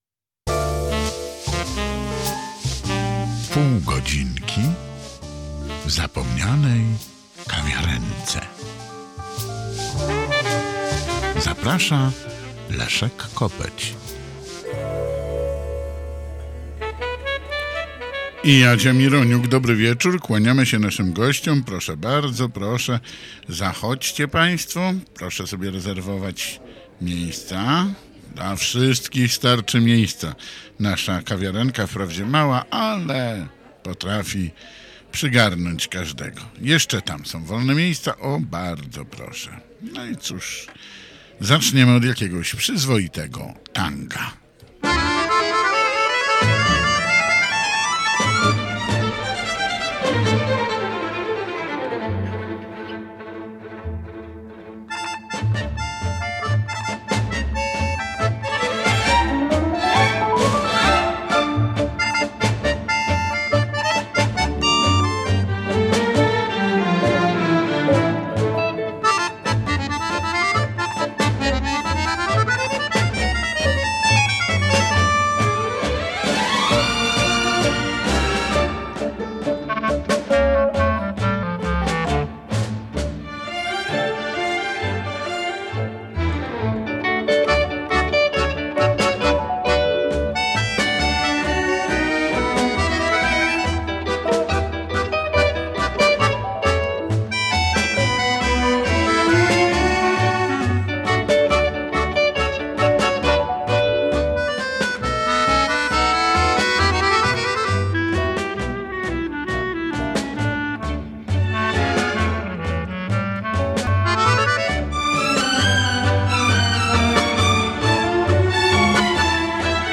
Prezentowane są polskie piosenki z lat: 30,50, 60 ubiegłego stulecia.